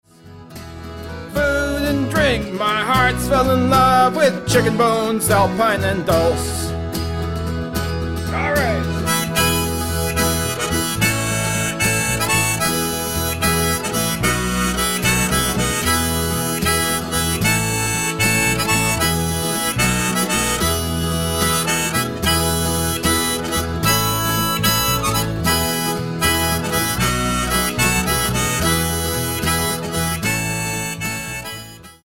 - uptempo Maritime waltz